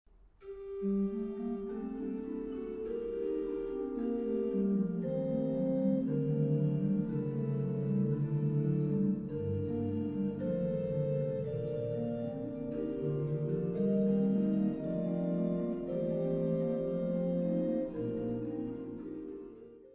Koororgel
De volgende geluidsfragmenten geven een indruk van de klankrijkdom van het Reil-koororgel.
Variatie 4: Holpijp 8′ van Hoofdwerk